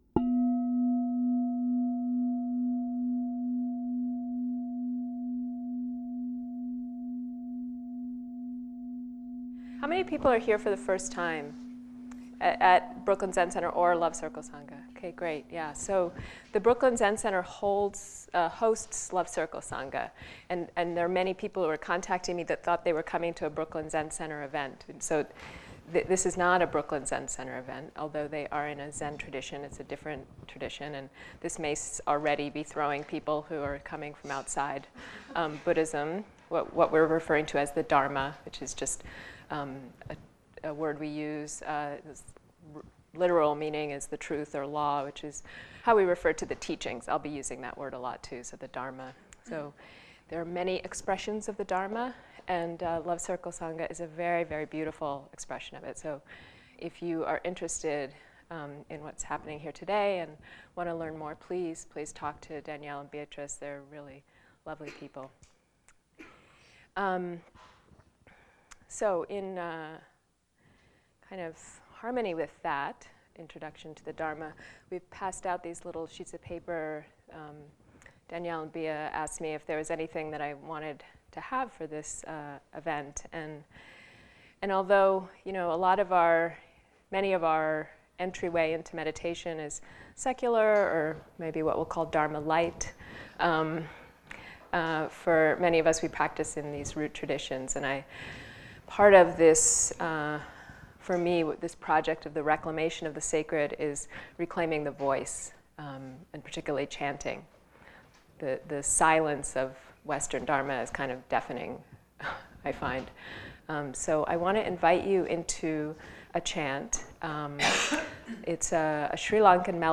This offering was made during a meeting of Love Circle Sangha, one of BZC’s affiliate sanghas.